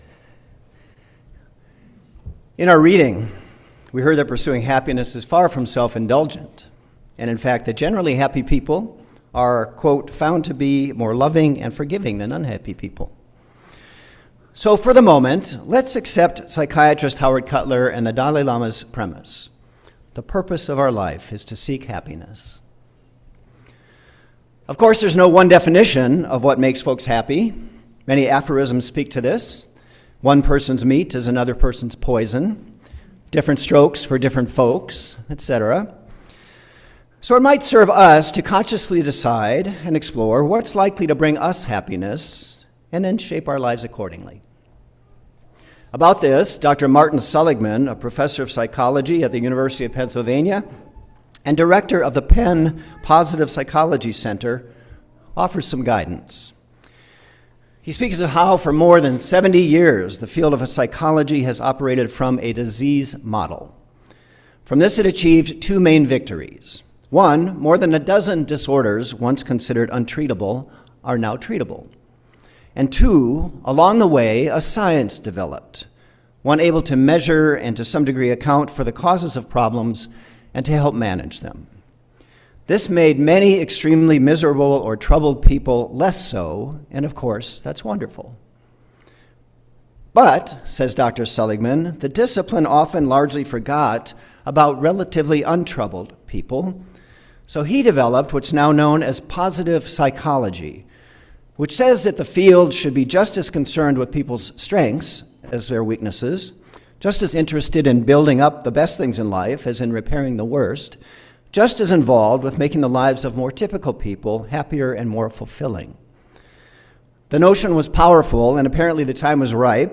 Sermon audio can be found on each service's page (select the service title below), followed by a video of the full service if available (starting April 12, 2020